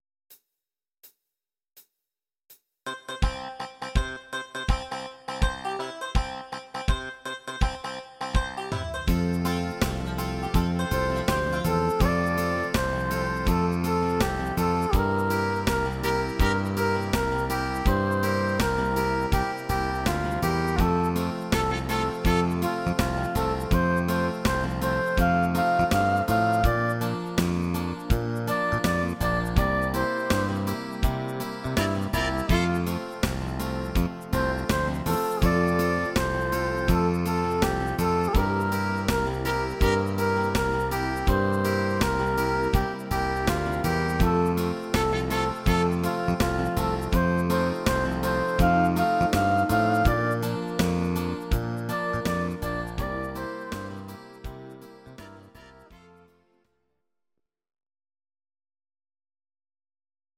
Audio Recordings based on Midi-files
German, 1970s